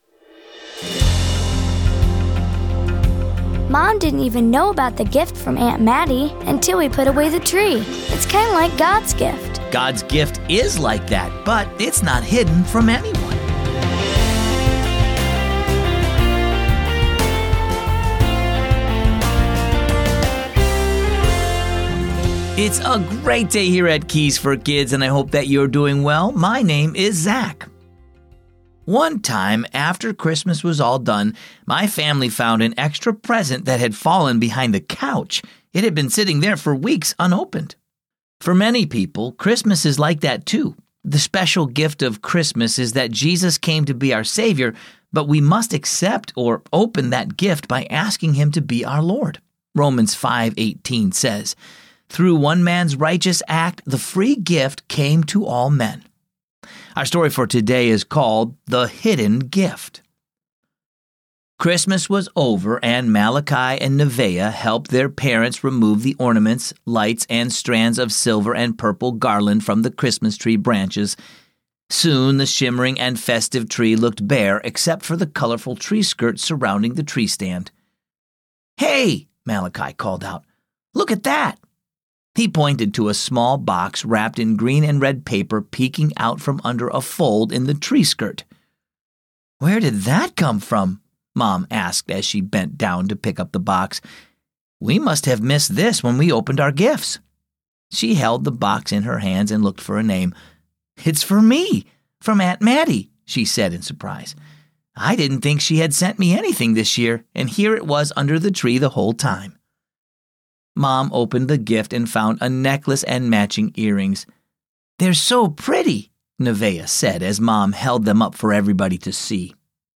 creative voices bringing characters to life